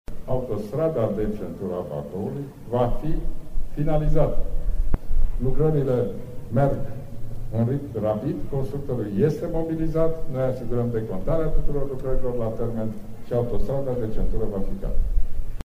Prim-ministrul Ludovic Orban, preşedintele PNL, a prezentat, ieri la un eveniment de campanie electorală la Bacău, proiectele pe care Guvernul le are în infrastructura rutieră şi feroviară din judeţele Moldovei.